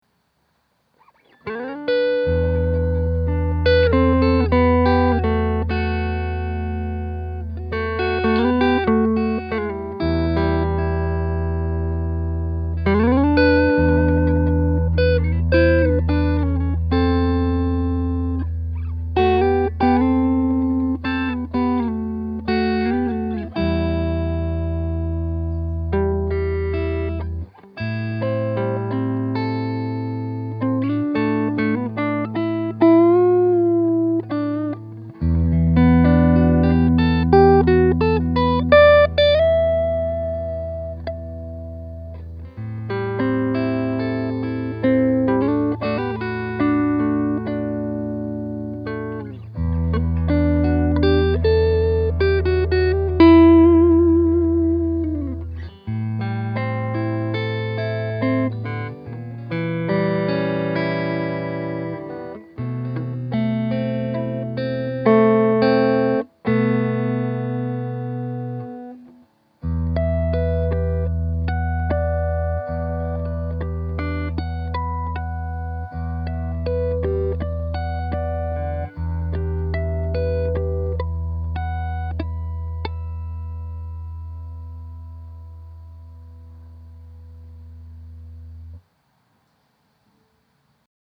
I put together some quick clips to demonstrate the sound of this guitar (all clips were played through my beloved Aracom PLX18 “plexi” clone):
Next, I just started playing some random stuff fingerstyle in the neck pickup: